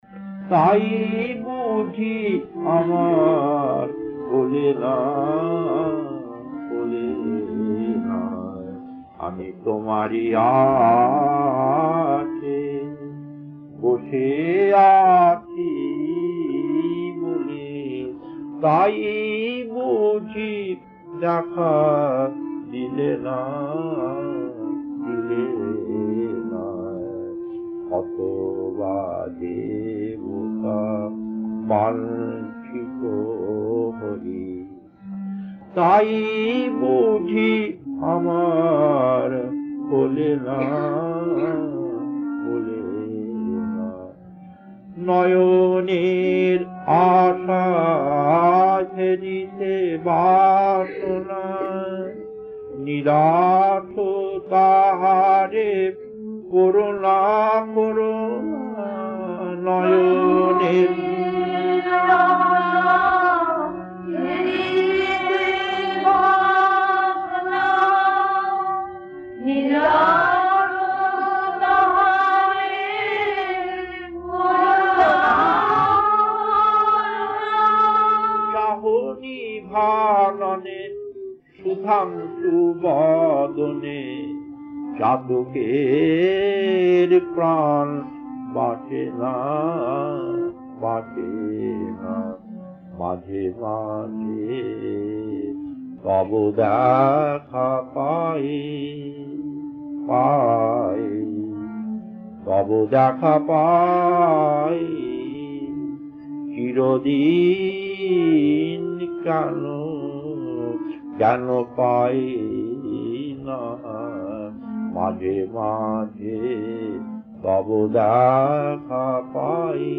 Kirtan E2-1 1.